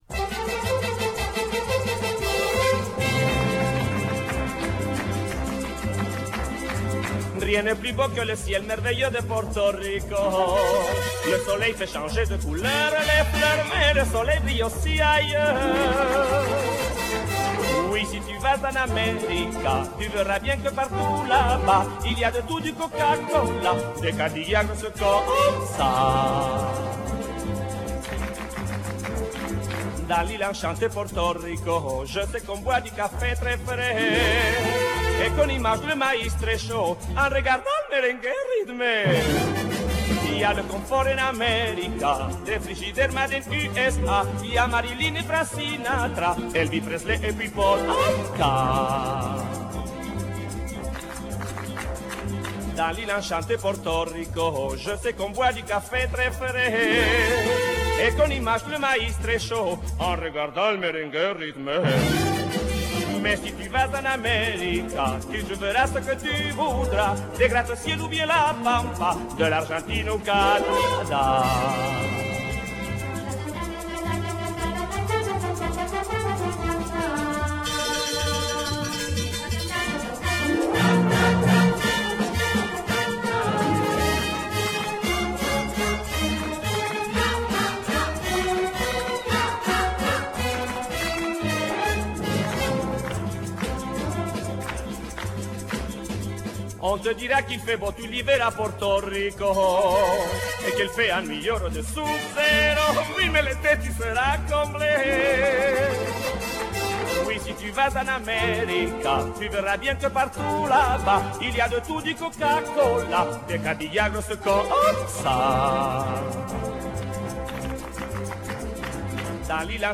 with a precision in rhythm and pitch like nobody else.